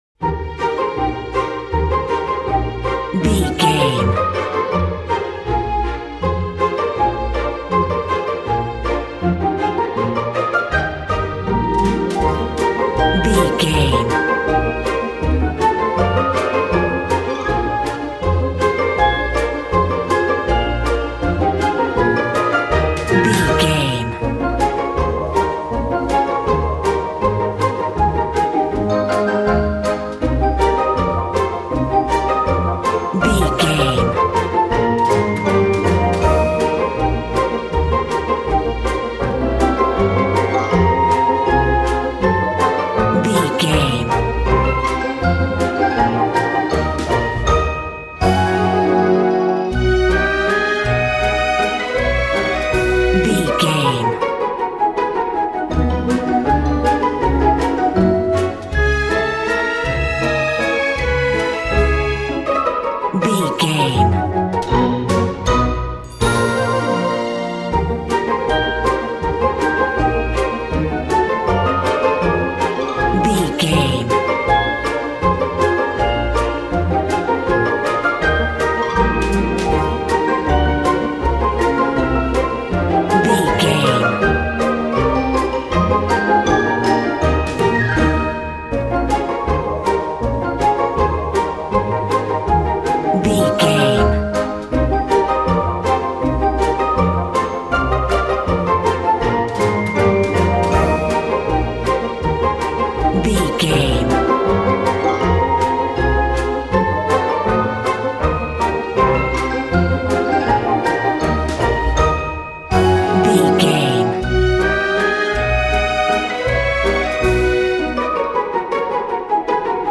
Ionian/Major
A♭
cheerful/happy
dreamy
orchestra
strings
playful